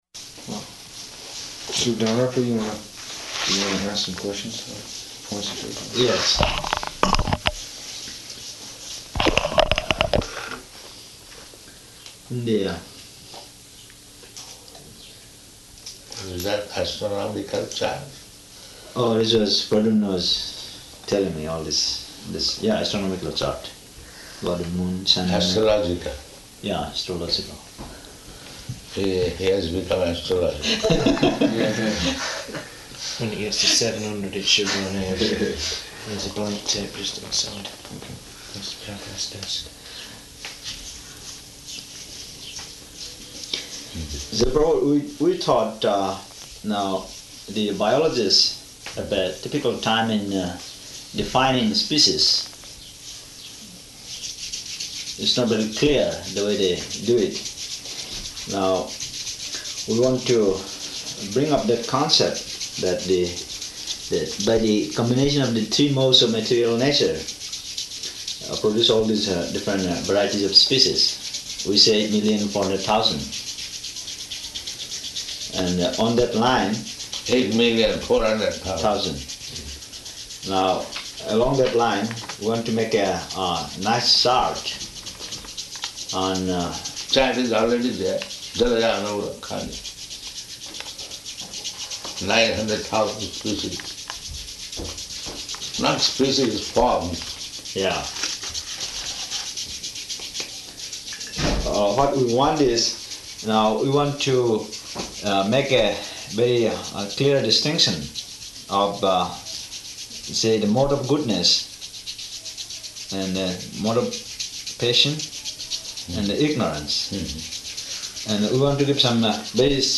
Type: Conversation
July 4th 1976 Location: Washington, D.C. Audio file